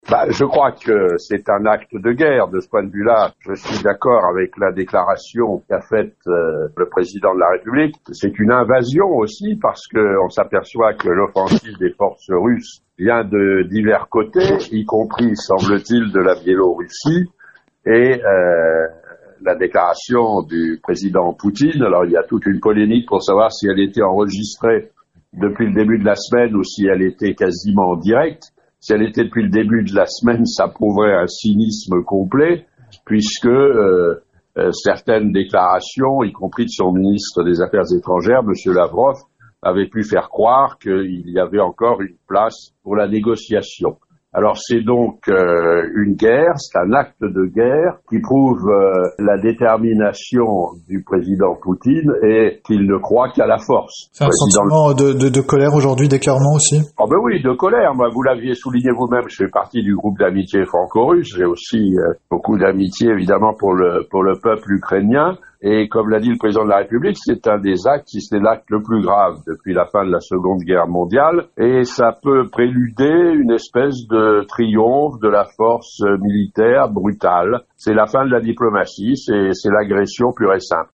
Didier Quentin réagit après les bombardements et l’invasion russes ce matin. Le député Les Républicains de la Charente-Maritime, qui est membre de la commission des affaires étrangères et vice-président du groupe d’amitiés franco-russe à l’Assemblée nationale, dénonce les attaques coordonnées diligentées par le président Poutine.
Les propos de l’ancien diplomate et député royannais Didier Quentin.